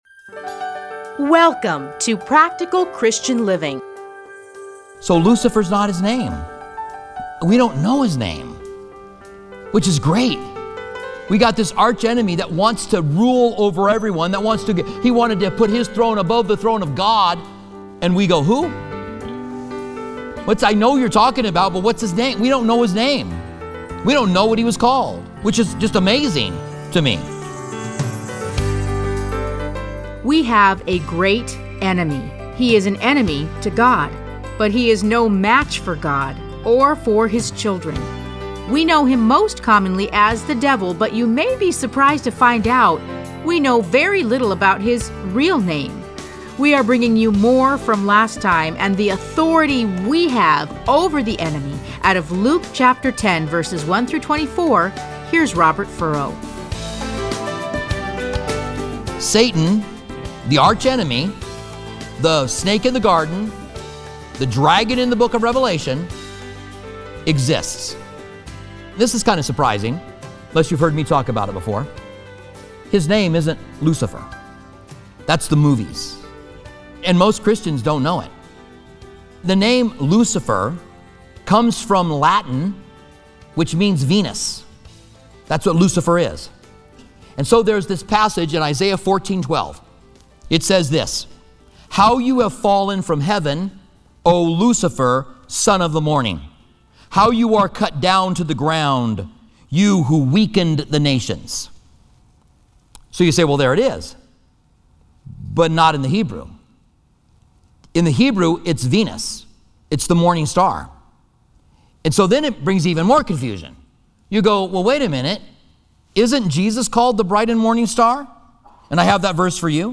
Listen to a teaching from Luke Luke 10:1-24.